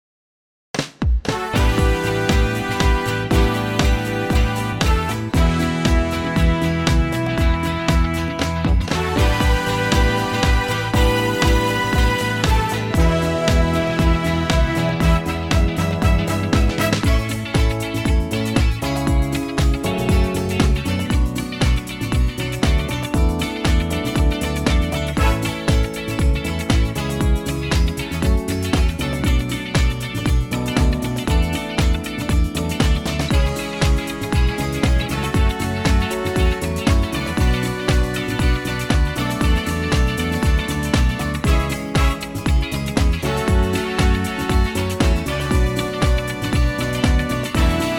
key - A - vocal range - B to D
Superbly fresh and punchy arrangement